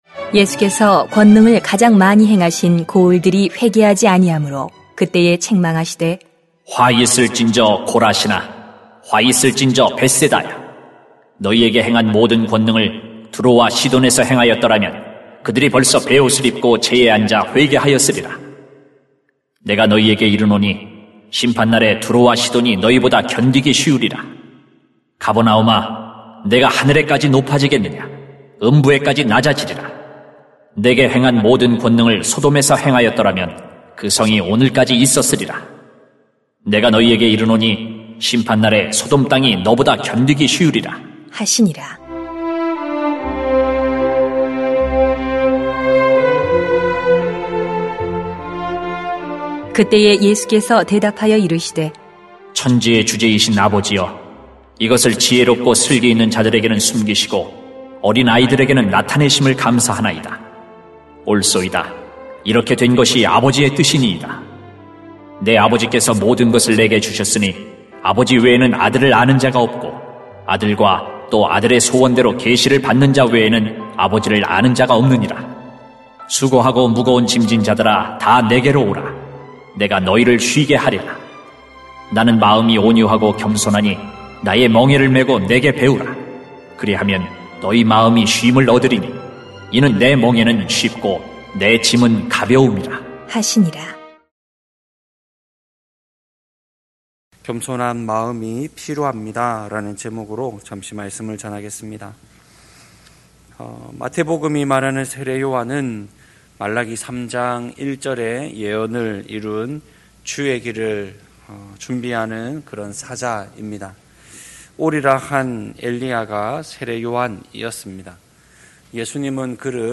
[마 11:20-30] 겸손한 마음이 필요합니다 > 새벽기도회 | 전주제자교회